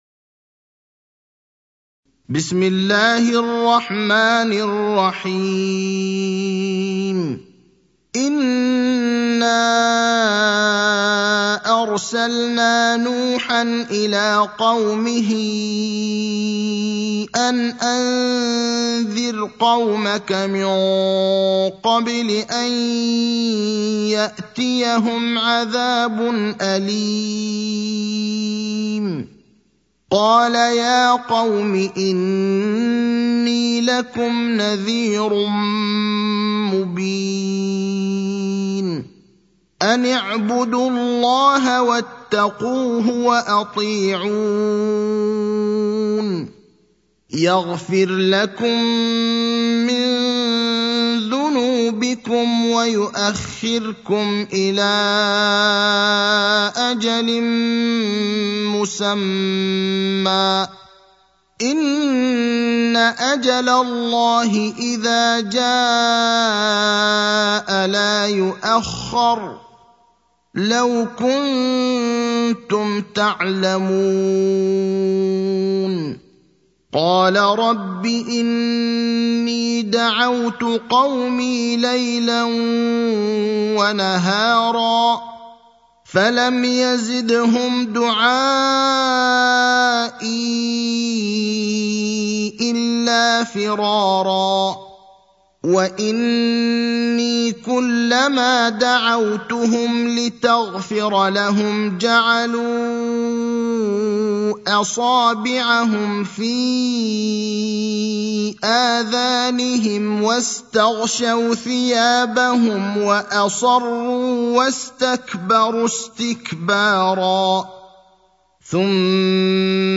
المكان: المسجد النبوي الشيخ: فضيلة الشيخ إبراهيم الأخضر فضيلة الشيخ إبراهيم الأخضر نوح (71)